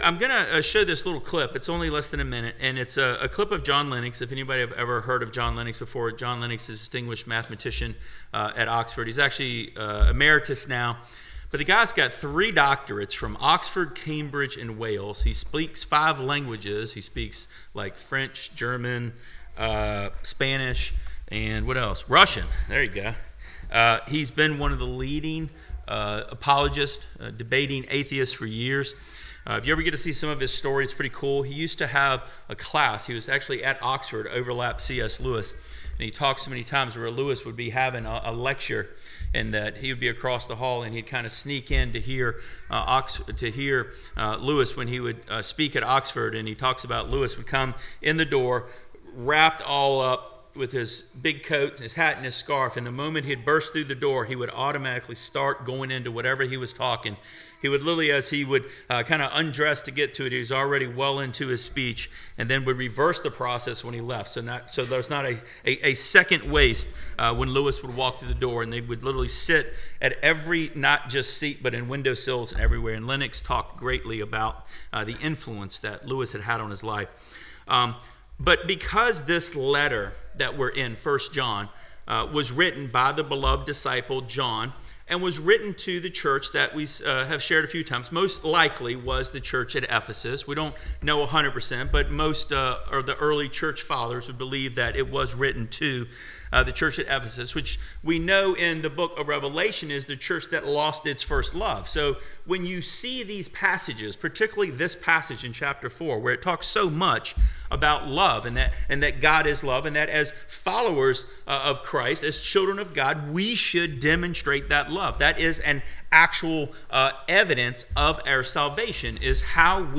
The sermon highlights the inconsistency of claiming to love God while hating a brother, emphasizing that loving the visible neighbor is essential to loving the unseen